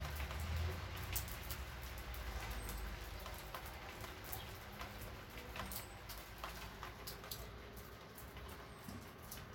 Database dei canti ed altre vocalizzazioni
ho un altro riconoscimento da farvi effettuare, scusate l'audio poco chiaro e con pioggia. Il verso é quel "pii...pii...pii" che si sente ogni 5-6 secondi.